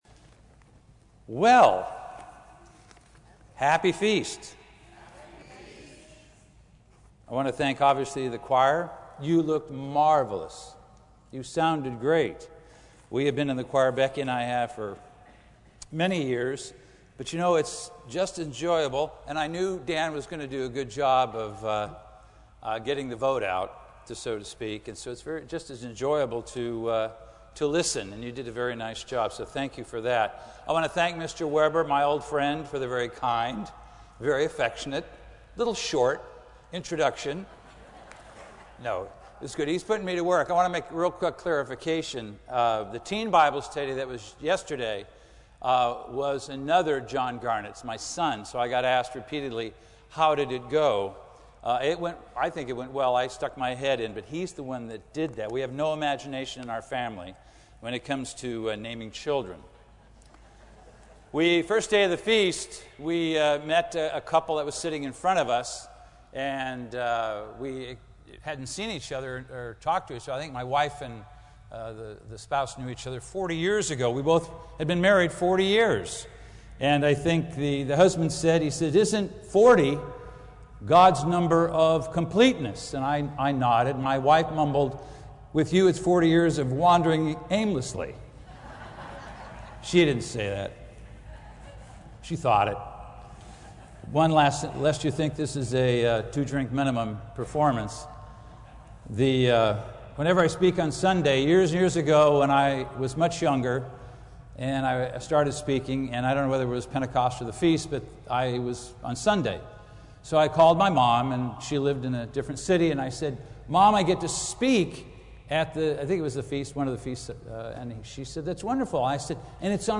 This sermon was given at the Oceanside, California 2017 Feast site.